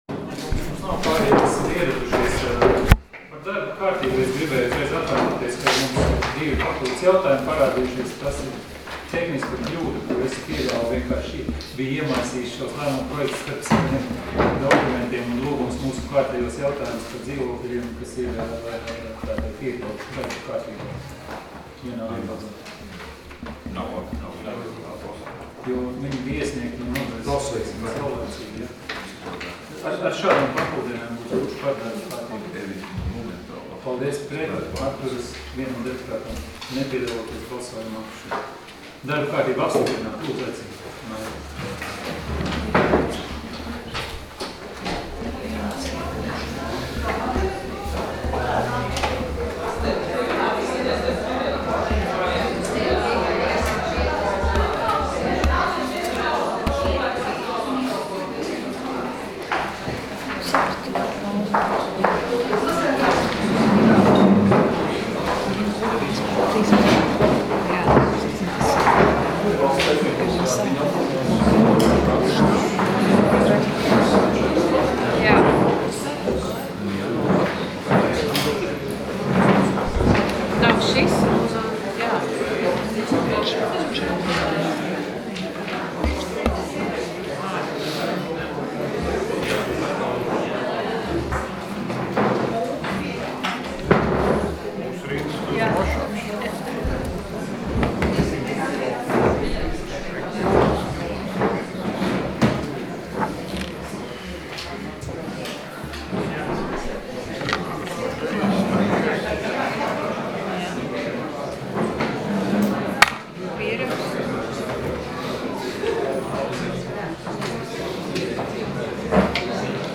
Domes sēdes 10.06.2016. audioieraksts